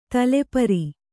♪ tale pari